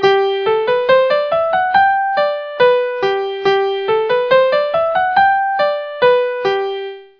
Q:1/4=140
a simple scale in G Note that the ABC takes care of sharpening the f automatically.